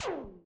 lasershoot.ogg